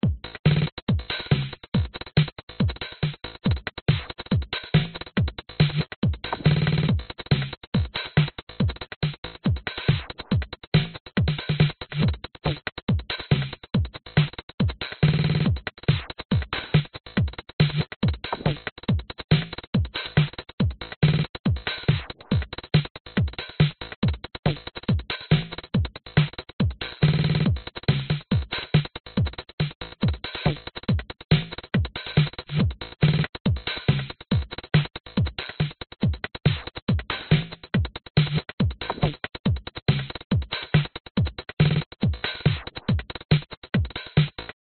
描述：一个突兀的节拍，有点四平八稳的感觉。
Tag: 节拍 舞蹈 鼓声 电子 突发事件